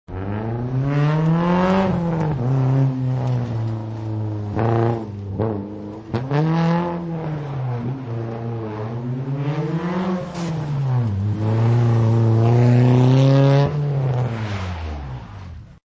Изменение звуков машин
Sports car.mp3